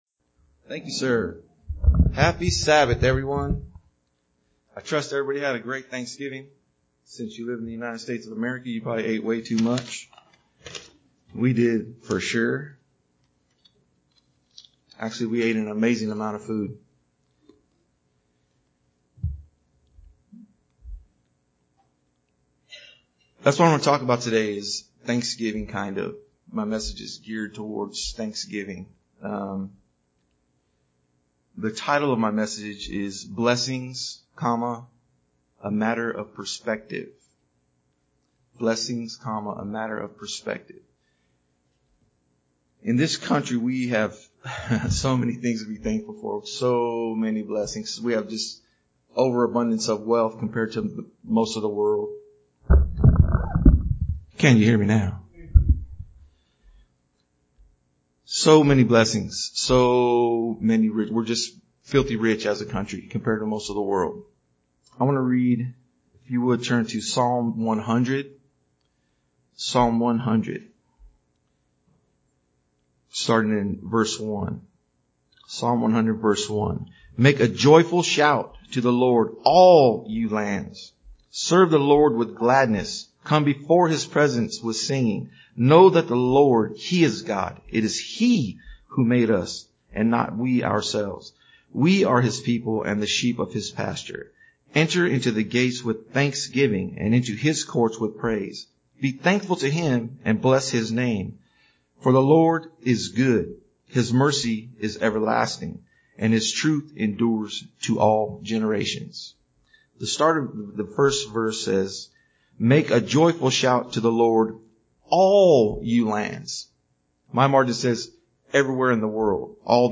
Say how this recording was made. Given in Jonesboro, AR